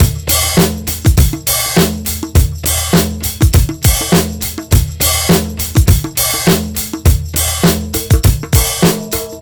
TR BEAT 1 -L.wav